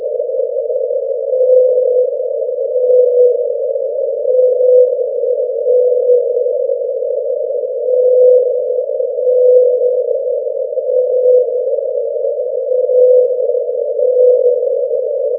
Blue_whale_atlantic2.ogg